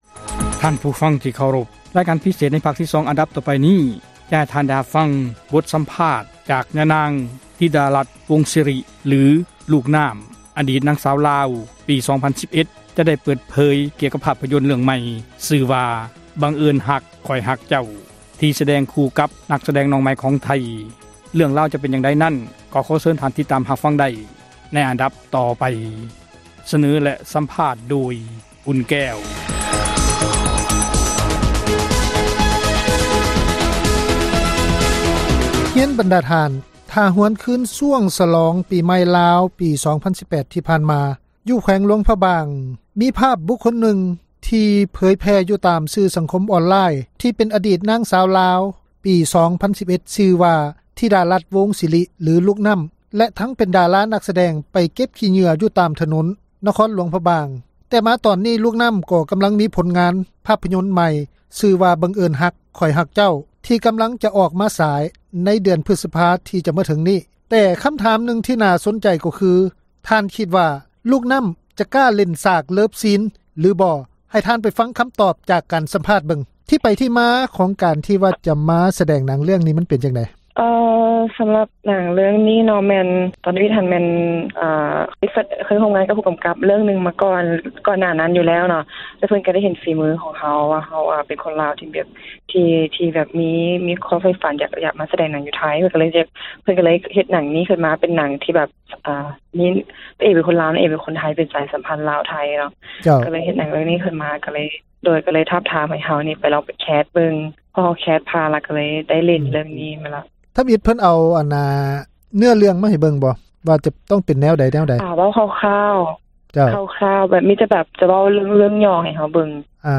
ສໍາພາດ ລູກນ້ຳ ທິດາລັດ ວົງສິລິ